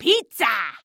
Бабушка радостно улыбнулась при виде пиццы